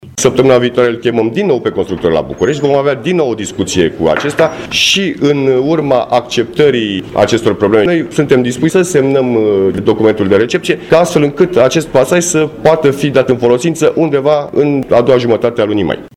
Aici se desfășura o conferință de presă, legată de tergiversarea dării în folosință a pasarelei peste centura ocolitoare a municipiului Brașov, din dreptul localitatății Sânpetru.
Prezent la conferința de presă